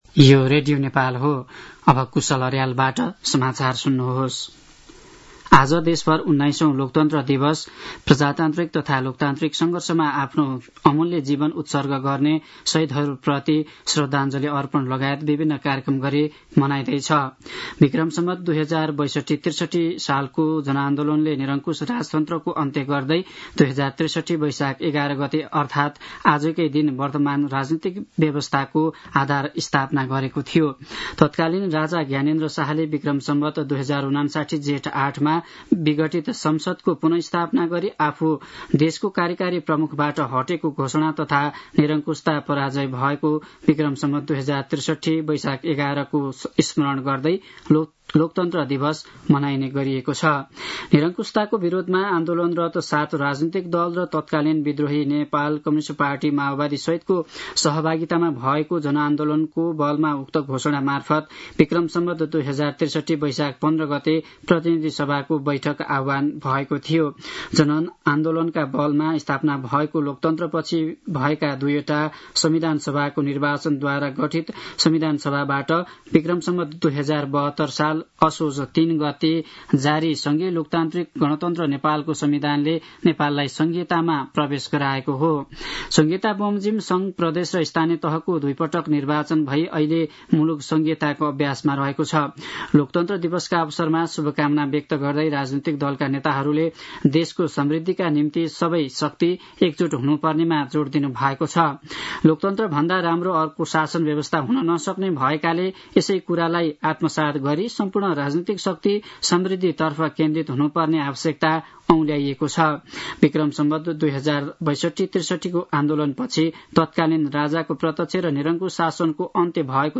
दिउँसो ४ बजेको नेपाली समाचार : ११ वैशाख , २०८२
4-pm-news-1-9.mp3